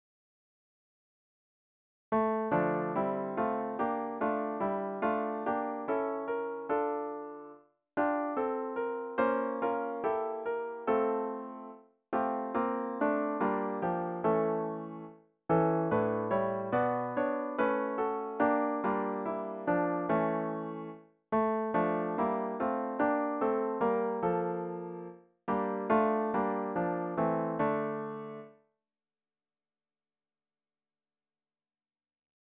About the Hymn